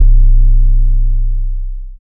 808 [Sub].wav